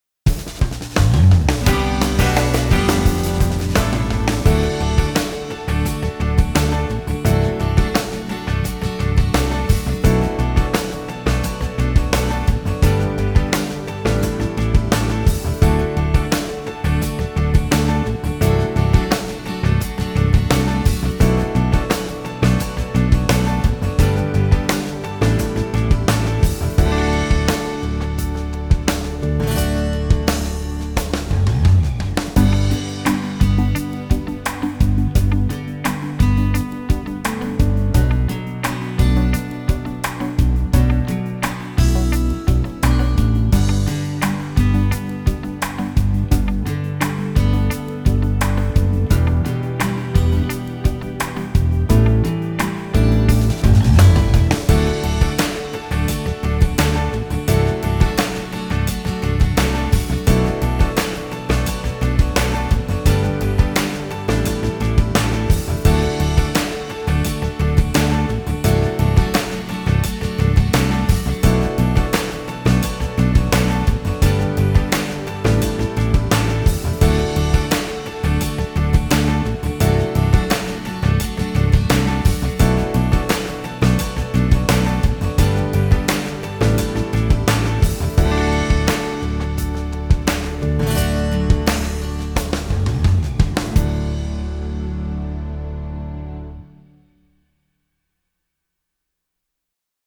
Tu ścieżka dźwiękowa piosenki: